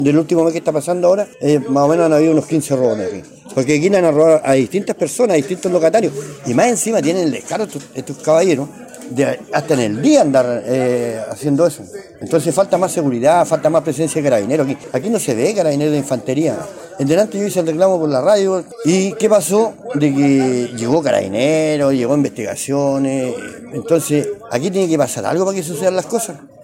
en conversación con Radio El Carbón